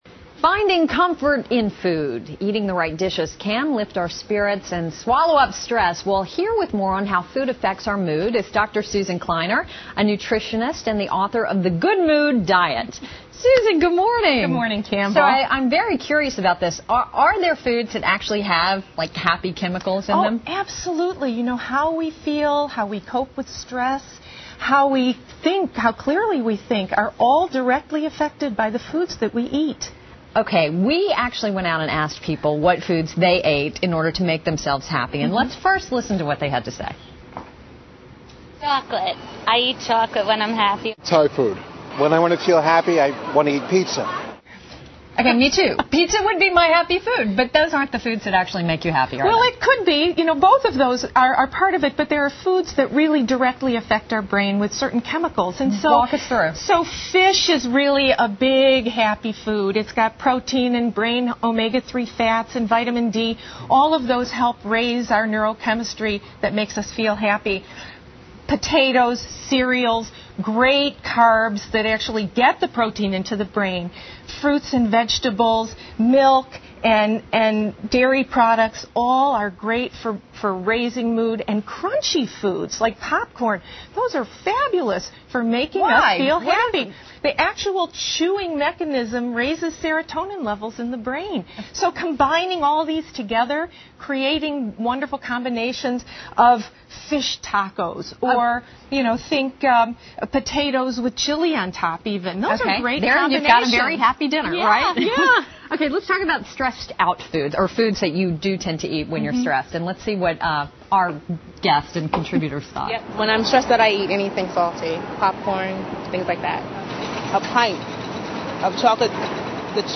访谈录 Interview 2007-04-12&14, 寻找取悦自己的食物 听力文件下载—在线英语听力室